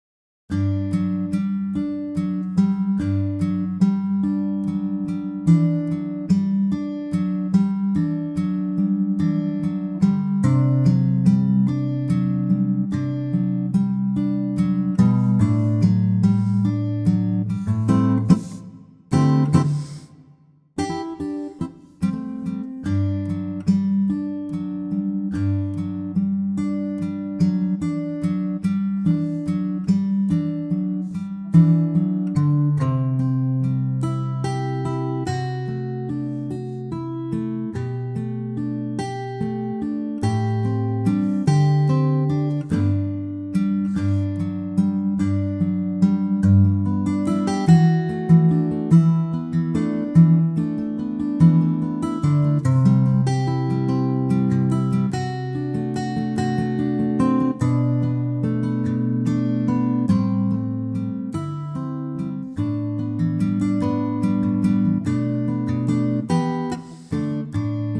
key G (key change to Bb and B for the chorus)
key - G (Bb and B for the chorus) - vocal range - B to F#
An intimate take
with acoustic guitar only.